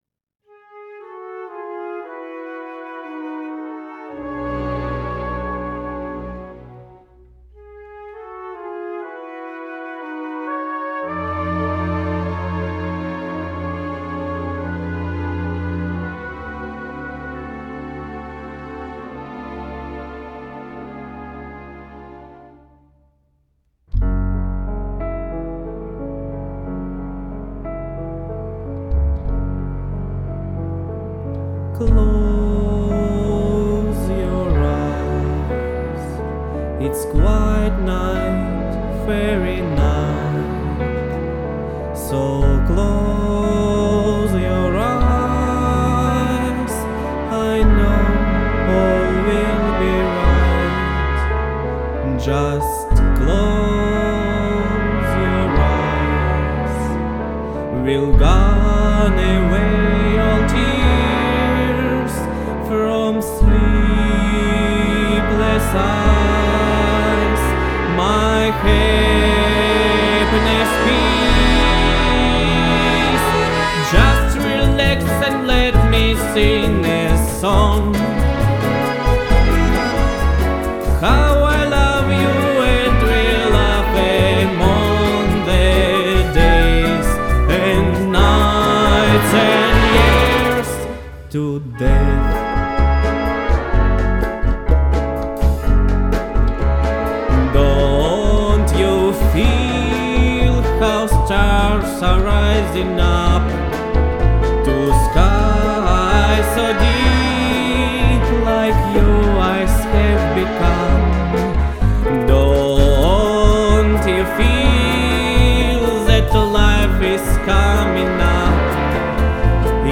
bossa nova
Так что это колыбельная, в стиле босса-нова (ну, или ближе всего к ней).